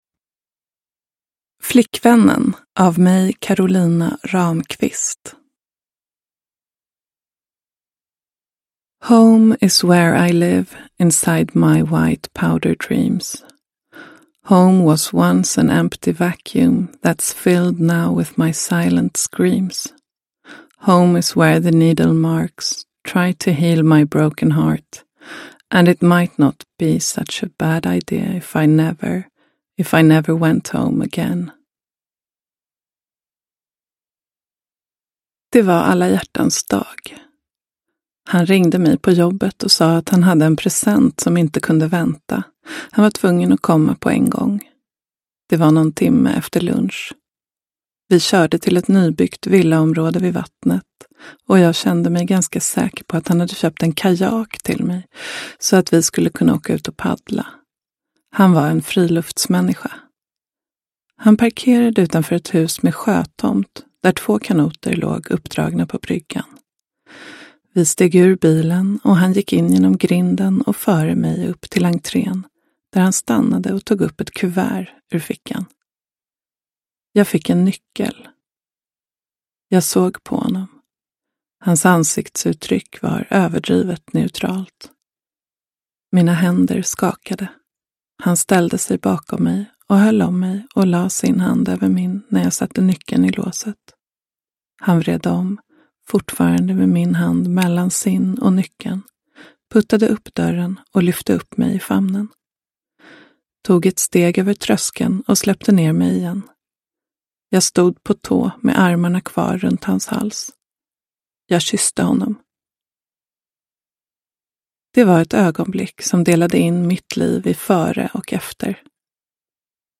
Uppläsare: Karolina Ramqvist